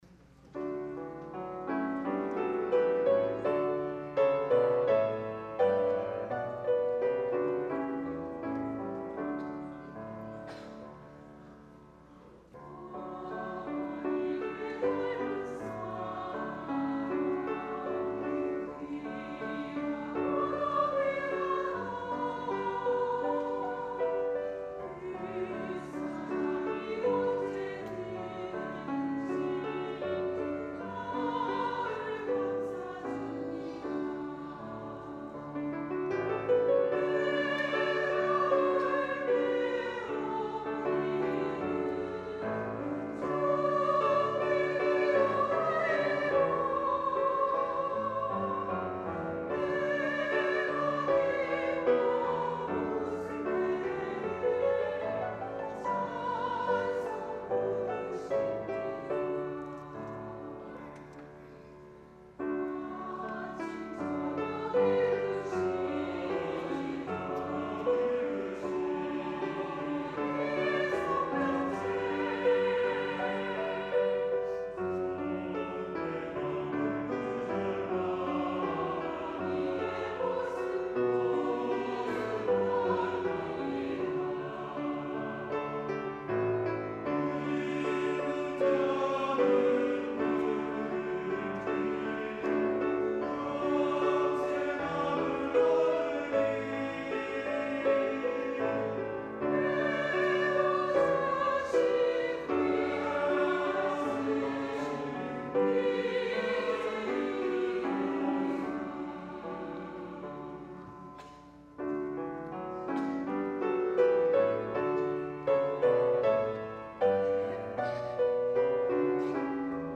" 어머니의 넓은 사랑 "- 시온찬양대 -